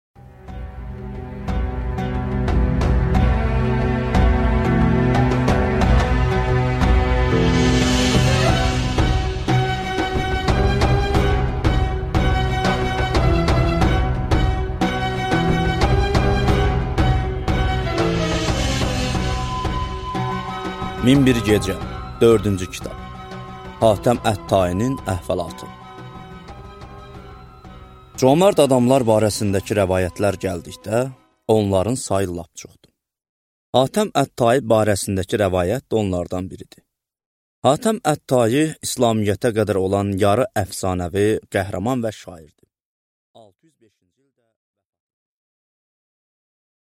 Аудиокнига Min bir gecə 4-cü cild | Библиотека аудиокниг